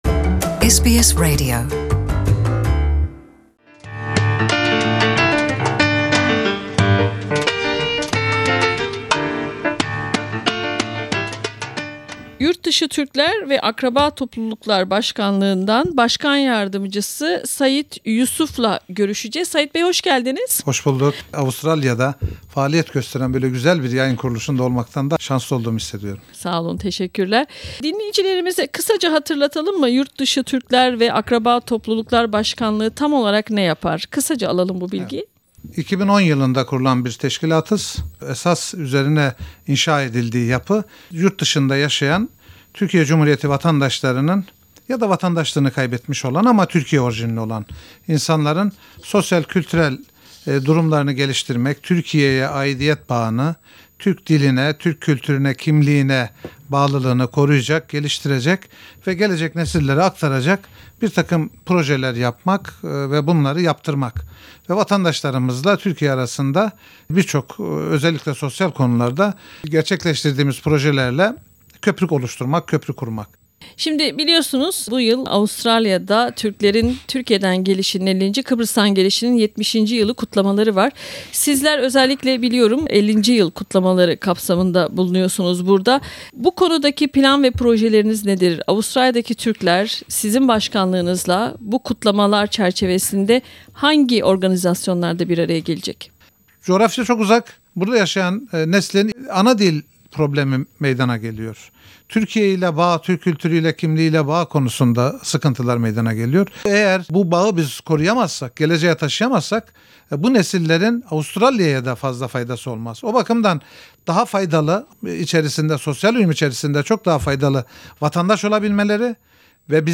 Türklerin Avustralya'ya Türkiye'den gelişinin 50'nci yıldönümü nedeniyle yapılacak konser ve bir dizi etkinlik için Avustralya'ya gelen Yurtdışı Türkler Ve Akraba Topluluklar Başkanlığı heyeti SBS Radyosu Türkçe programını da ziyaret etti. Başkan yardımcısı Sayit Yusuf ile heyetin yürüttüğü çalışmalar hakkında konuştuk.